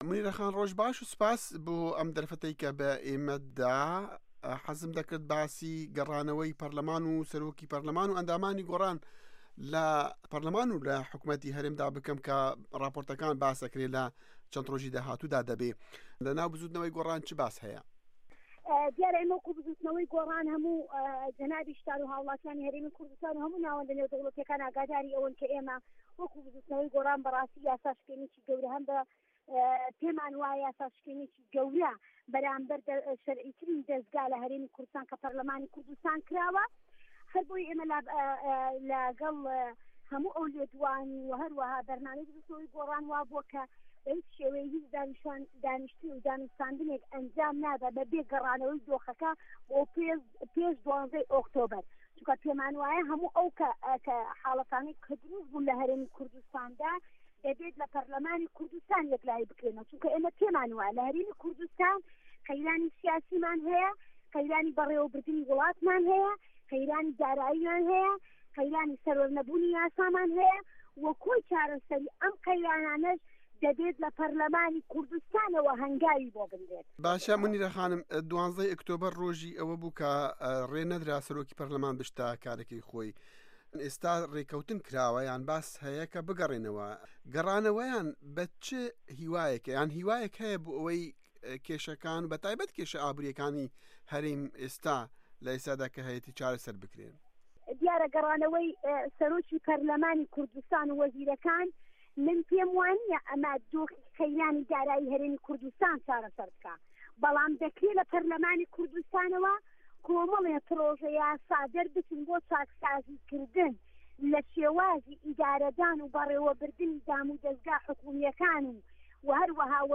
وتووێژ لەگەڵ مونیرە عوسمان